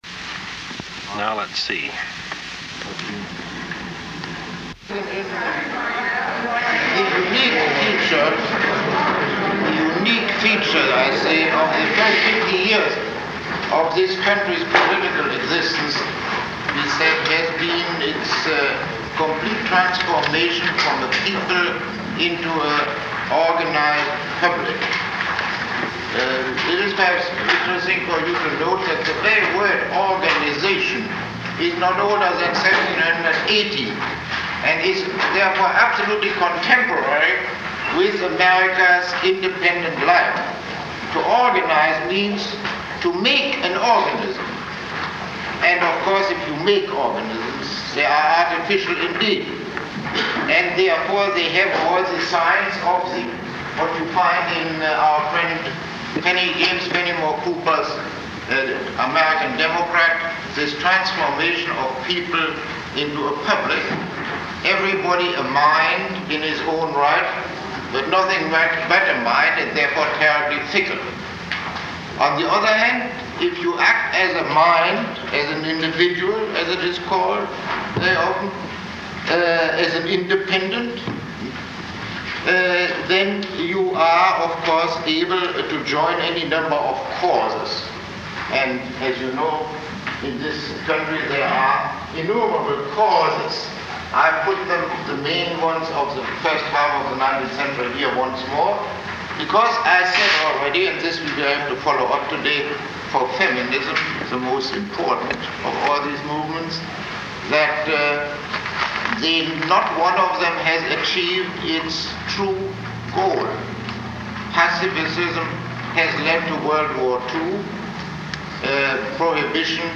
Lecture 18